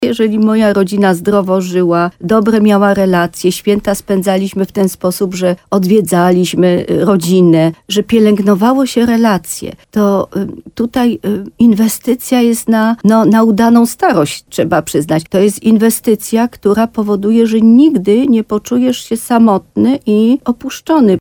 Postanowienia noworoczne coraz częściej oznaczają powrót do normalności [ROZMOWA]